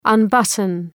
Προφορά
{ʌn’bʌtən}
unbutton.mp3